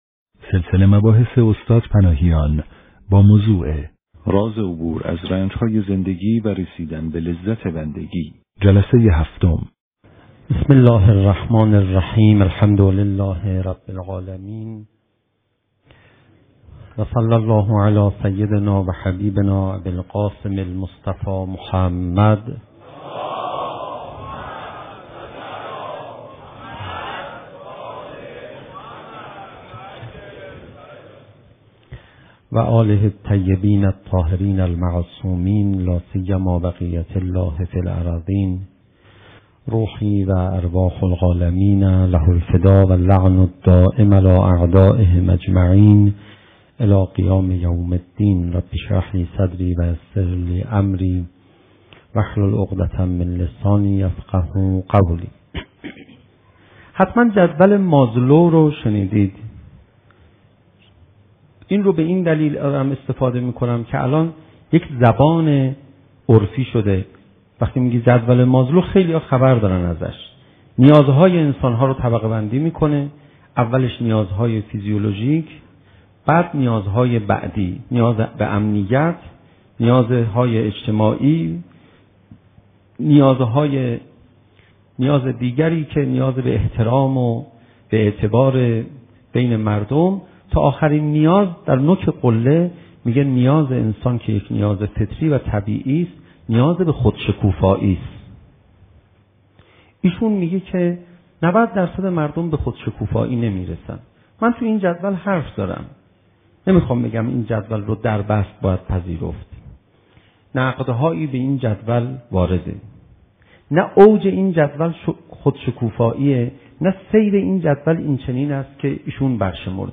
شب هفتم محرم 95_سخنرانی